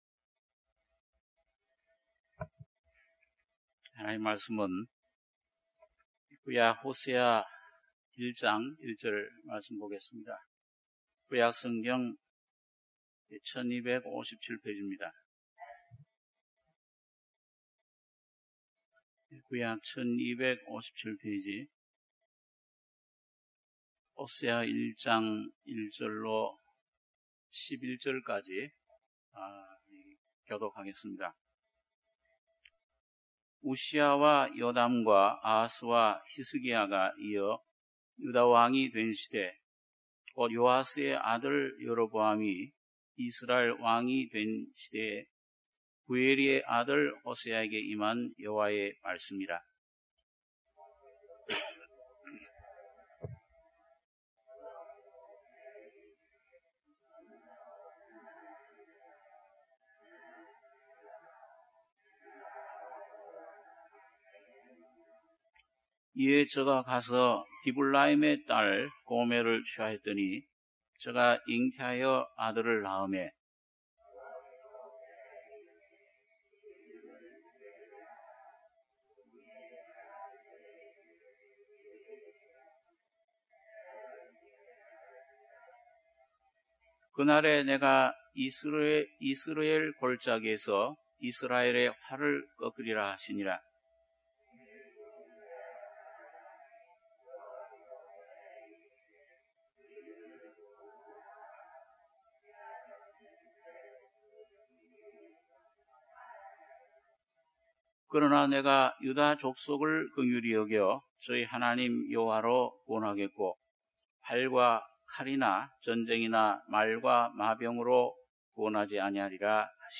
주일예배 - 호세아 1장 1절 ~11절